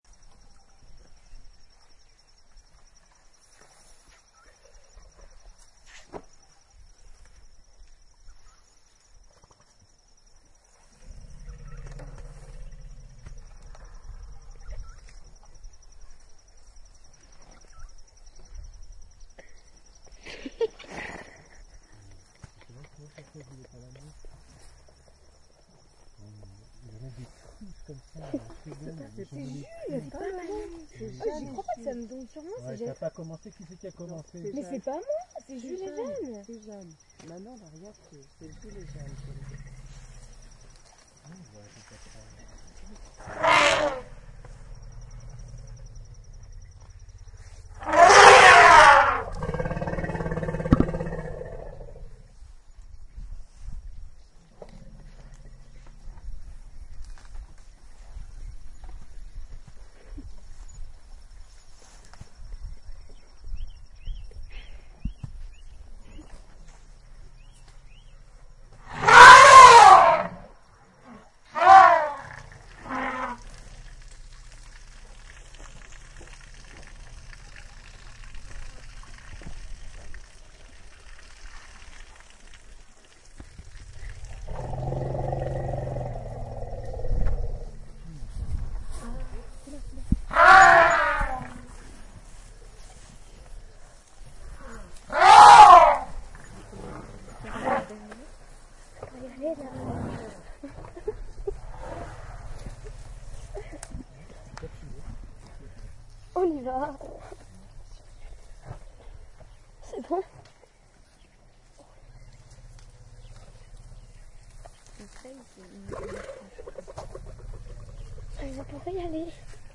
Elephant Téléchargement d'Effet Sonore
Elephant Bouton sonore